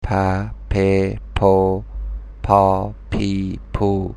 This is 'p' in Persian. Try to pronounce it with the six vowels.